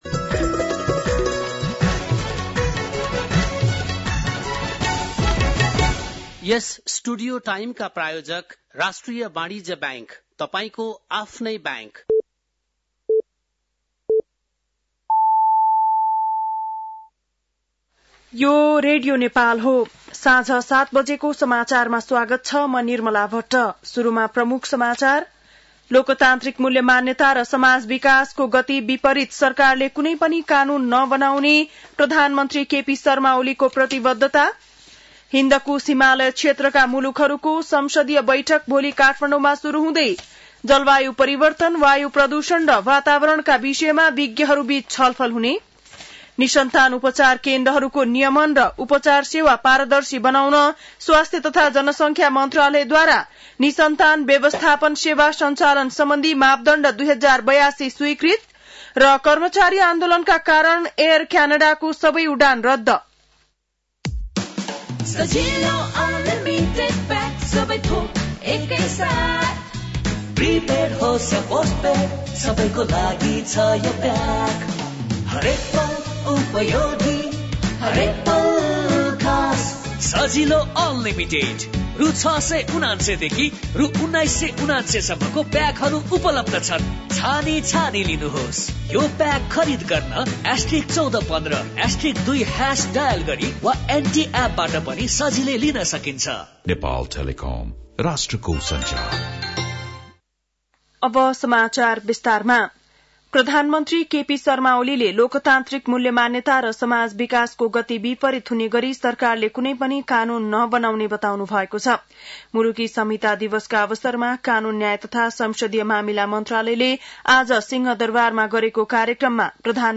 बेलुकी ७ बजेको नेपाली समाचार : १ भदौ , २०८२
7-pm-nepali-news-5-01.mp3